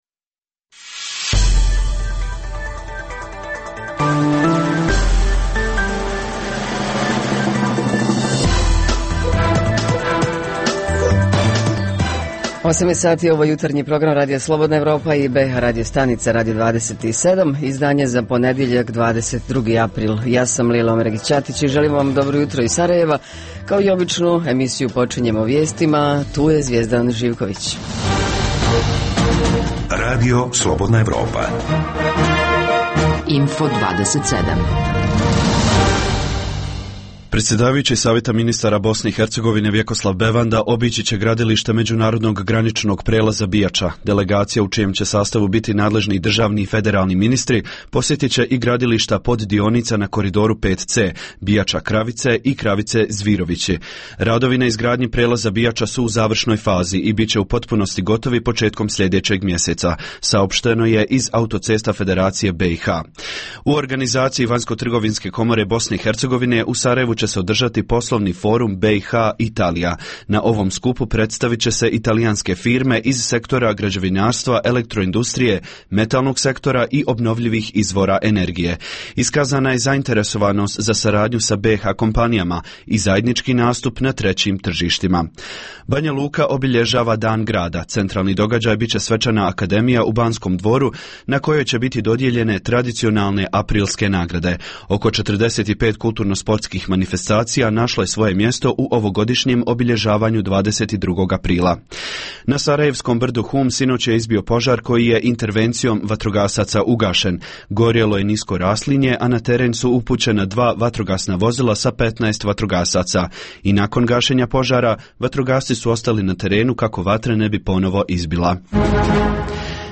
U emisiji Radija Slobodna Evropa i bh radio stanica - Radio 27, kao i obično, fini jutarnji miks tema i muzike: Obilježavamo Dan planete Zemlje, govorimo o Festivalu srednjoškolskog dramskog stvaralaštva, vrijednostima arhivistike, pokretanju malog biznisa, uspješnosti terapije smijehom, upravljanju otpadom pa sve do povratničkih tema. A sve ove priče jutros stižu od naših dopisnika iz Bijeljine, Konjica, Banja Luke, Zavidovića, Doboja i Goražda.